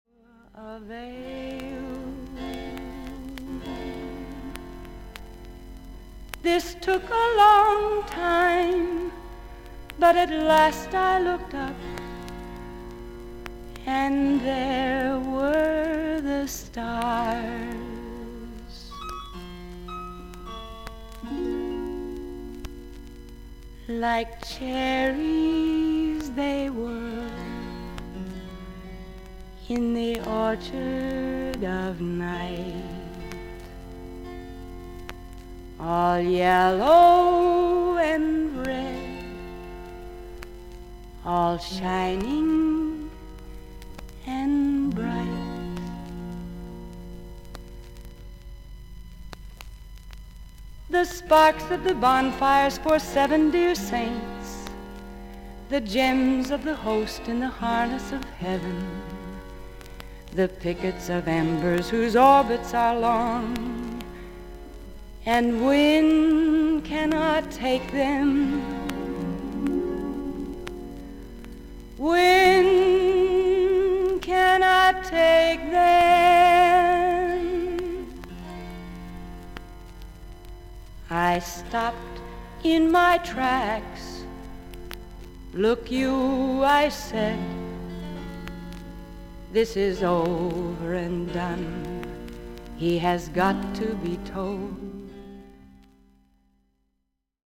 A2中盤に5mmのキズ、周回ノイズがあります。
ほかはVG+:少々軽い周回ノイズの箇所あり。少々サーフィス・ノイズあり。音自体はクリアです。